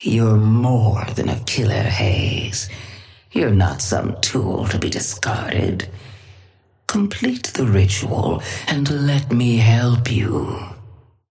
Patron_female_ally_haze_start_05.mp3